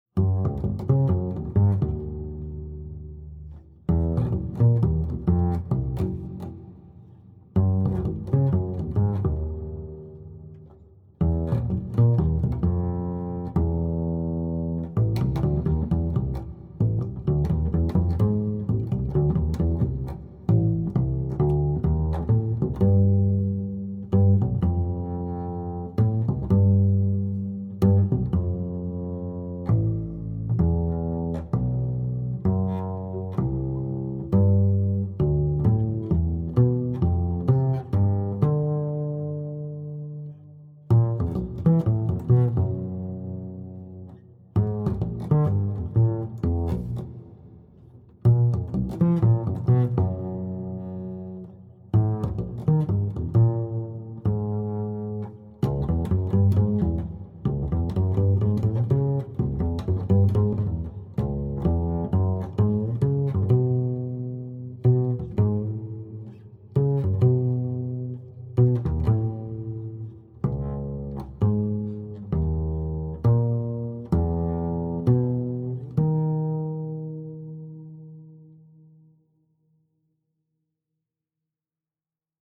chamber jazz-meets-contemporary classical niche